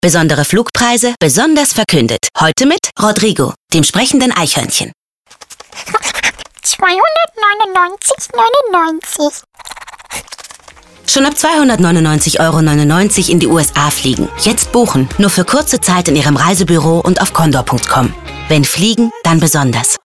Radio spots: